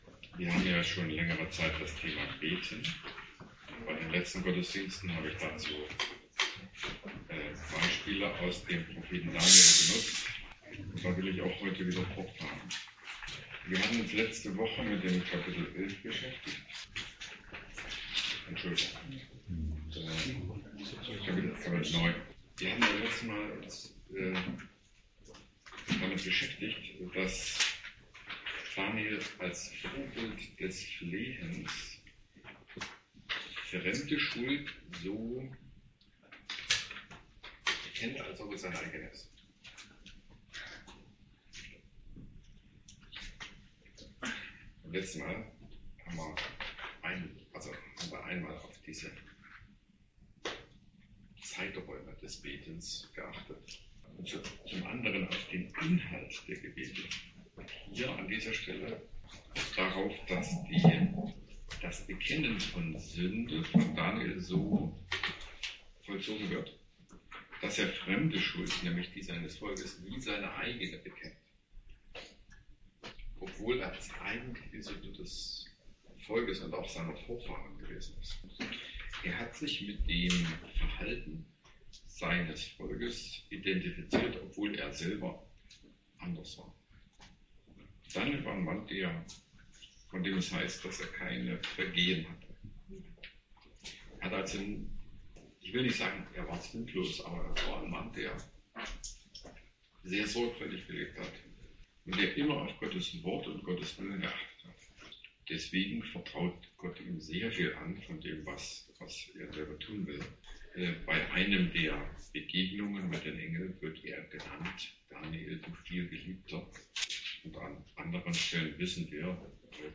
Predigt Daniel 9 Thema Gebet - Europäische Missionsgemeinschaft